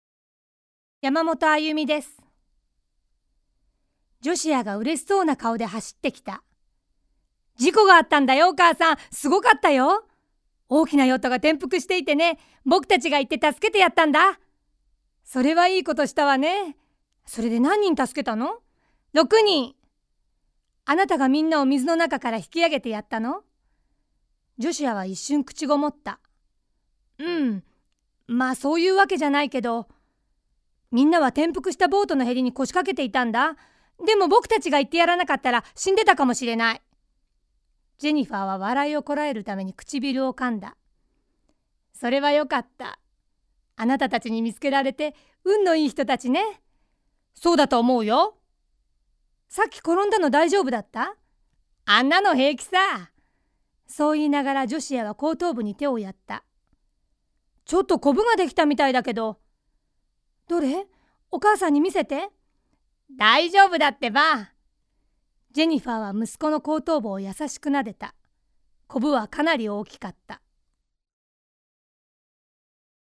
ボイスサンプルはこちら↓
ボイスサンプル